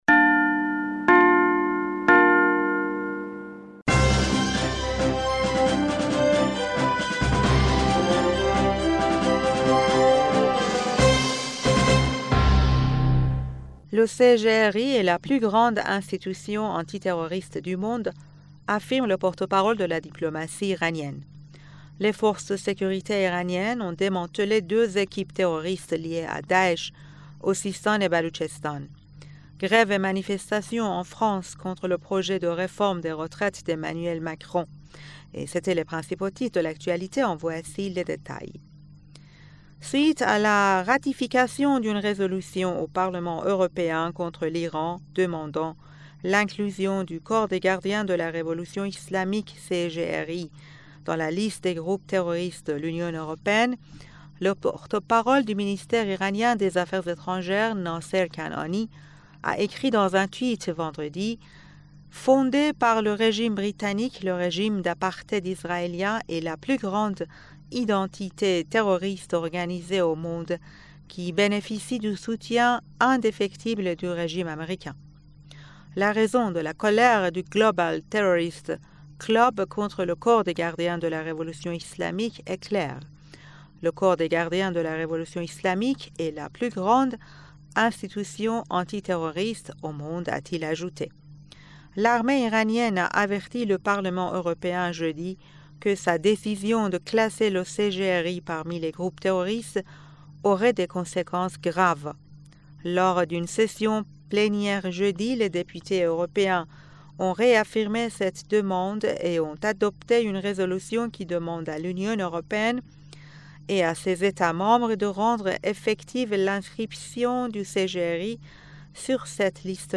Bulletin d'information du 20 Janvier